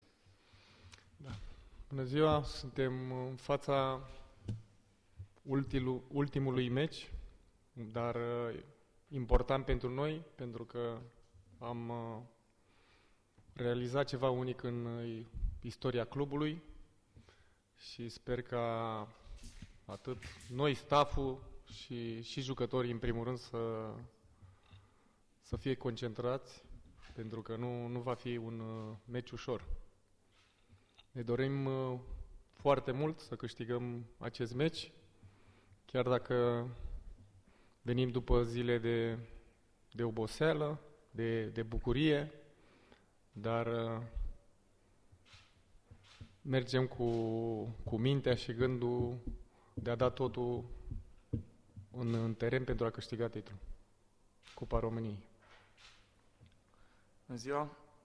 Există șanse chiar foarte mari ca el să joace în finala Cupei României”, a spus Costel Gâlcă, la conferința oficială de presă de dinaintea finalei.